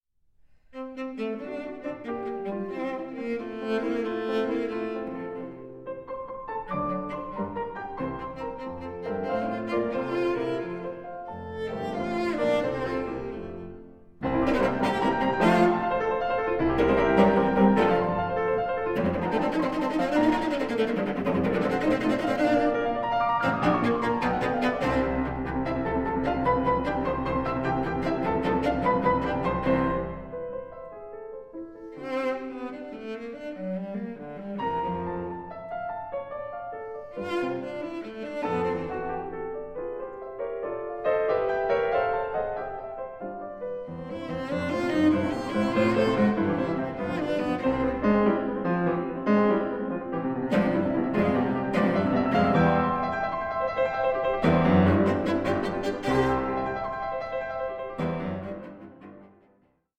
EQUAL, PROFOUND PARTNERSHIP OF CELLO AND PIANO